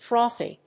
音節froth・y 発音記号・読み方
/frˈɔːθi(米国英語), frˈɔθi(英国英語)/